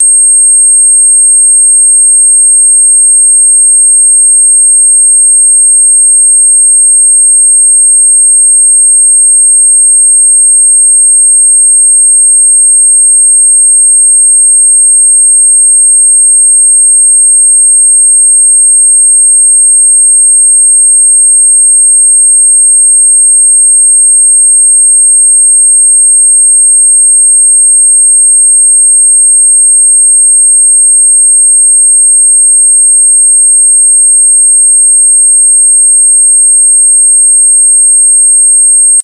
モスキート音として知られる高周波音の中でも、特に周波数8500Hzを持つ音を指します。
周波数8500Hz 着信音
この音は、年齢に関係なく60歳以上の方々にも聞こえる特徴があります。モスキート音は、一部の人々にとっては不快感やイライラを引き起こすことがあります。